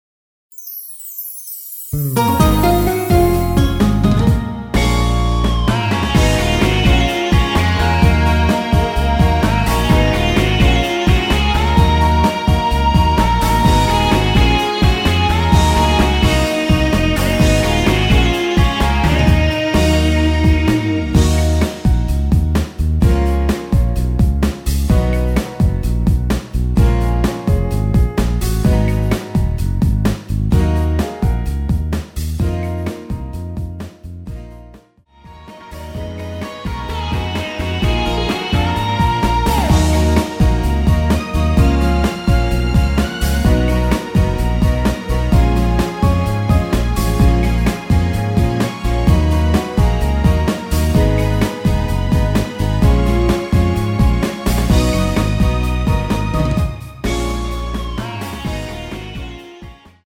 MR 입니다.
Ebm
앞부분30초, 뒷부분30초씩 편집해서 올려 드리고 있습니다.
중간에 음이 끈어지고 다시 나오는 이유는